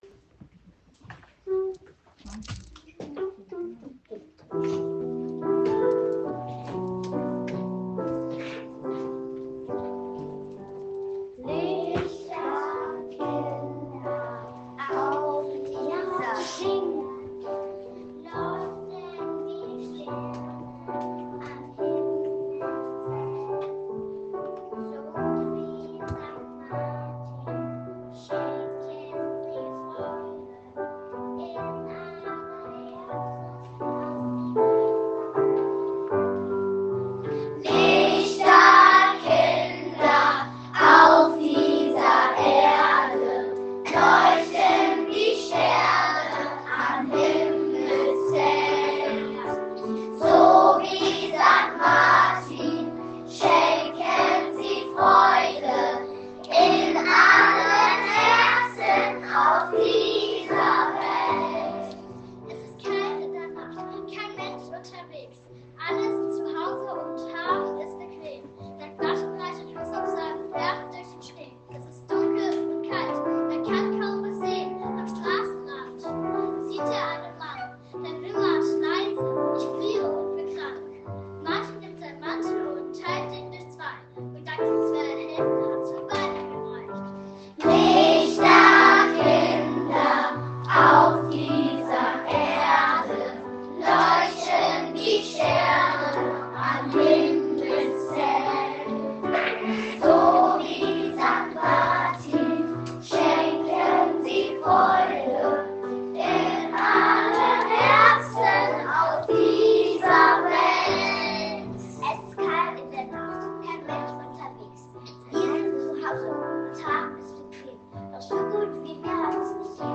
Sankt Martin in der Grundschule Hausen
Im Anschluss hörten wir einen fantastischen Liedbeitrag
unseres Schulchors
lichterkinder-schulchor.m4a